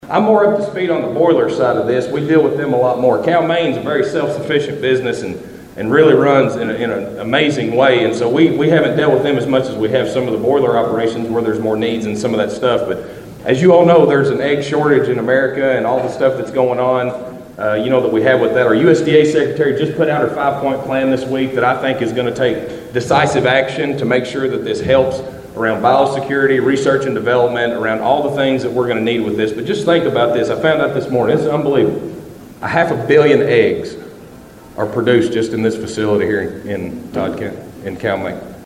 Shell was the featured speaker for the Todd County Agriculture Appreciation Breakfast at the Elkton Baptist Church Fellowship Hall Friday morning.